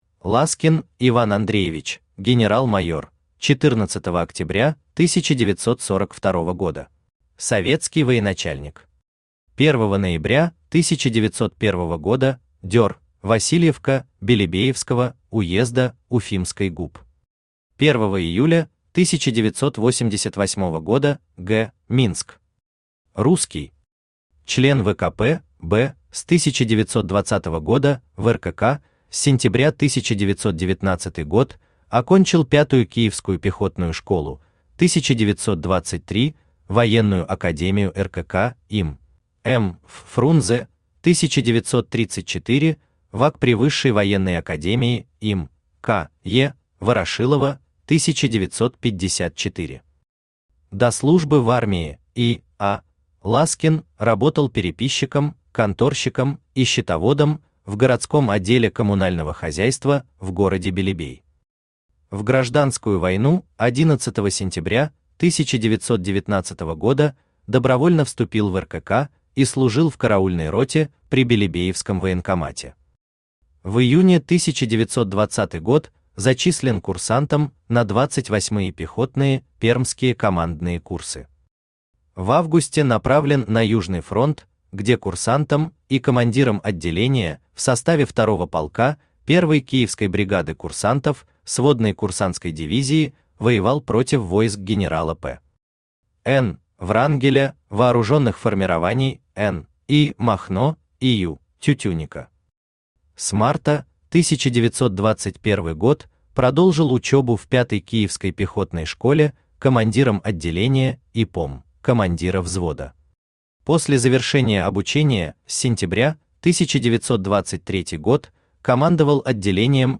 Aудиокнига Все генералы Сталина Том 20 Автор Денис Соловьев Читает аудиокнигу Авточтец ЛитРес.